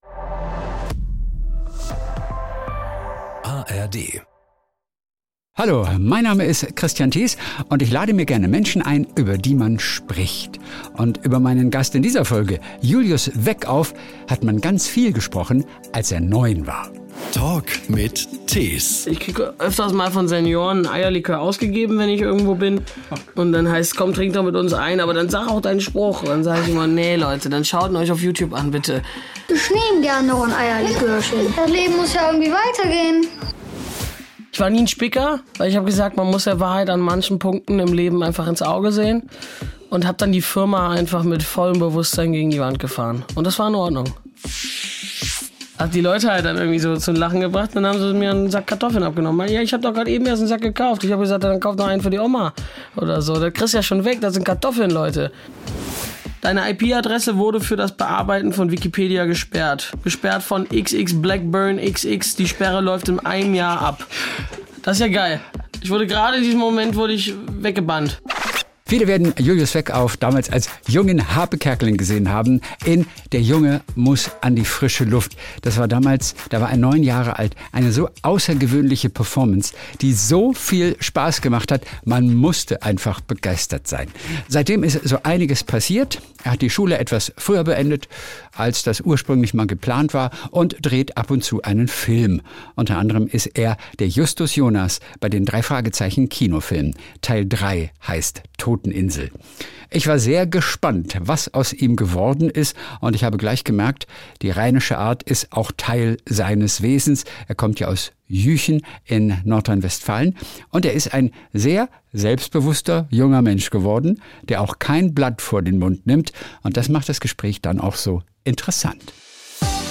In diesem Gespräch spricht Julius darüber, warum er die Schule früher beendet hat, als ursprünglich geplant – dabei spielt auch eine Lehrerin eine Rolle. Außerdem erfahrt ihr, warum Verkaufen möglicherweise sein größtes Talent ist und welcher legendäre Satz aus seinem ersten Film ihn bis heute verfolgt und nervt.